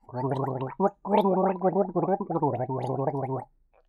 human
Mouth Gargling Music